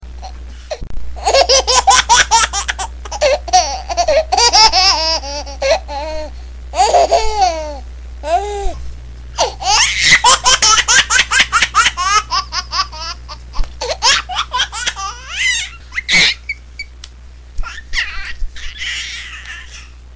نغمة صوت ضحك